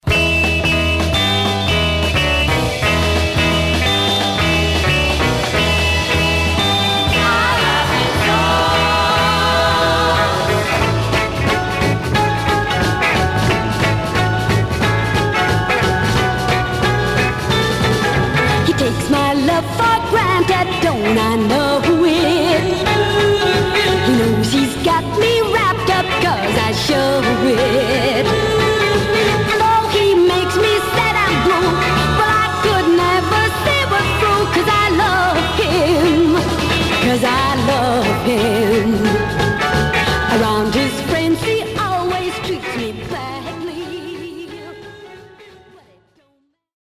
Surface noise/wear Stereo/mono Mono
White Teen Girl Groups